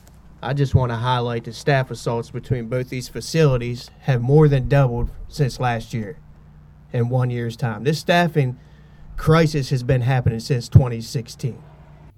A press conference held at the entrance to North Branch Correctional Institution on McMullen Highway Thursday drew attention to safety and critical staffing shortages at state facilities.